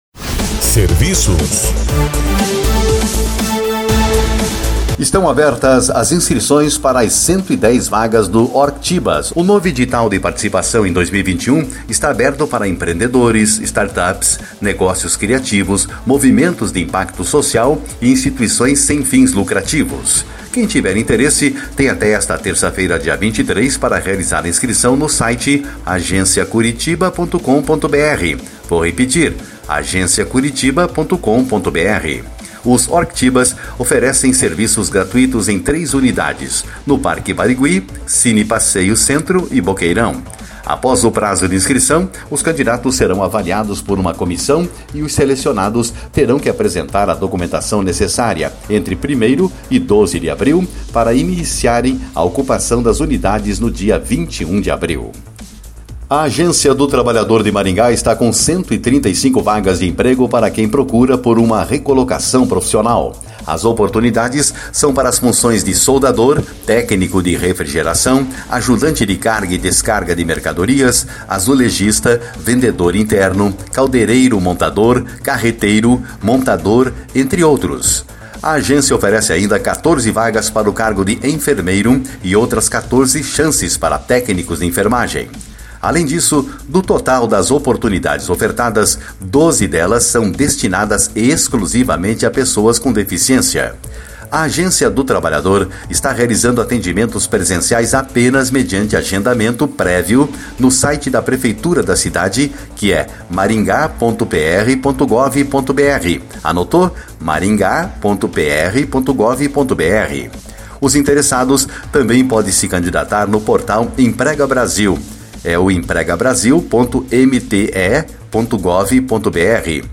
Inscrições para Worktibas estão abertas.// Agências do Trabalhador de Maringá têm 135 vagas de emprego.// Os serviços no boletim